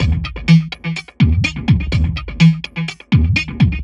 tx_perc_125_crunchyflange.wav